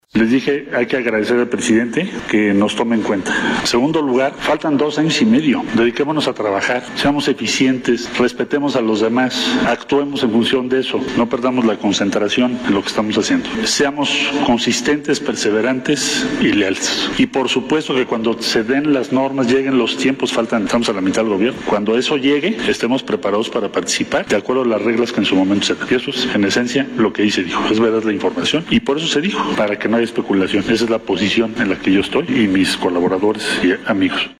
En la Conferencia Mañanera dijo que Ebrard puede ser un buen sucesor para su gobierno y pidió al canciller que tomara la palabra.